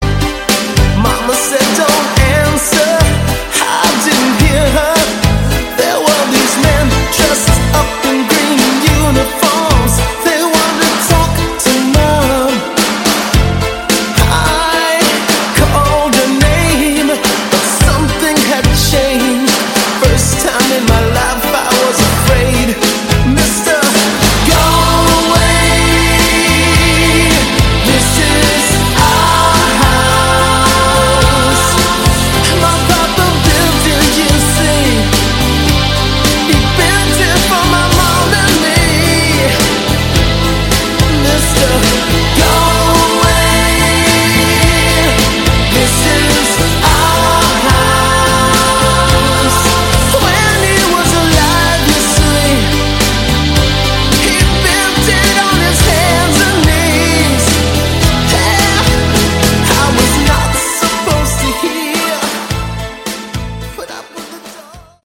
Category: Hard Rock
vocals, guitar, keyboards, bass
drums